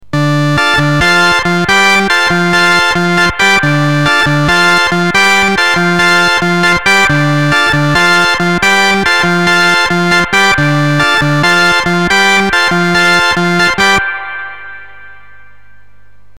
Demo1: 16' multitone-booster with all-booster on
Demo2: Same riff, but 16' and 8'